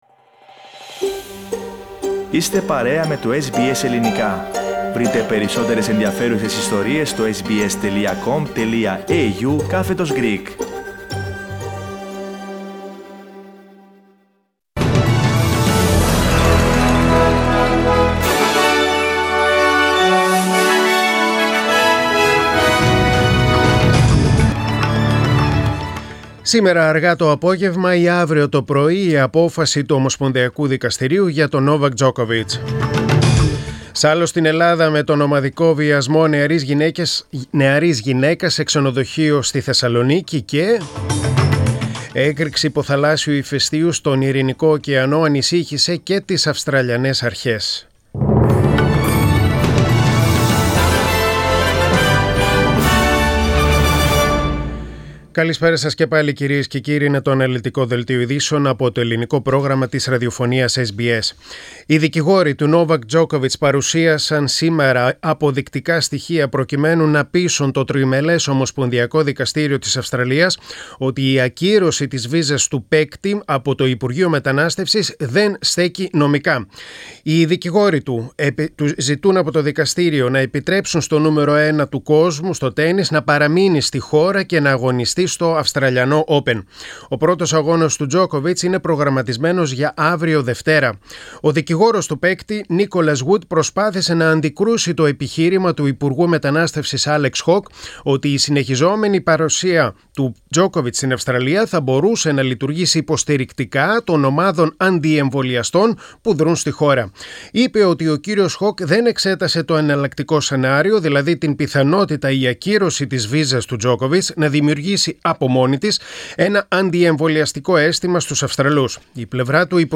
News in Greek: Sunday 16.1.2022